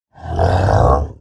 Minecraft Version Minecraft Version snapshot Latest Release | Latest Snapshot snapshot / assets / minecraft / sounds / mob / ravager / idle8.ogg Compare With Compare With Latest Release | Latest Snapshot